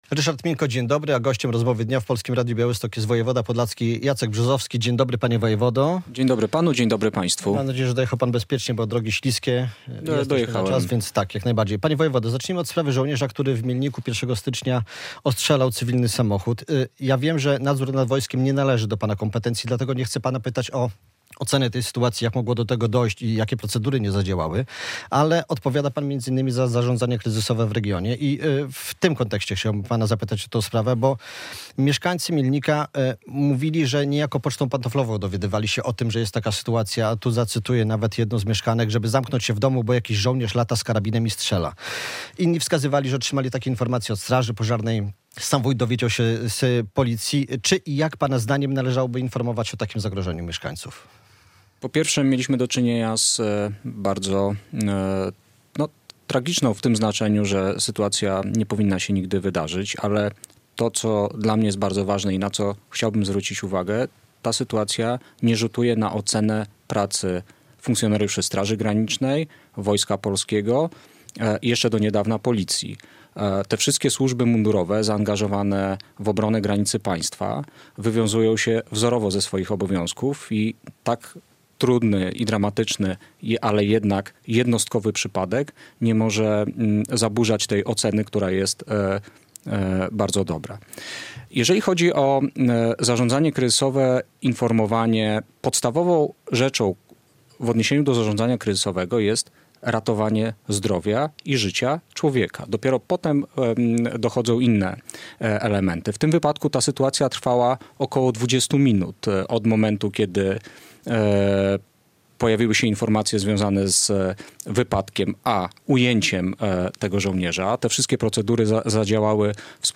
Radio Białystok | Gość | Jacek Brzozowski - wojewoda podlaski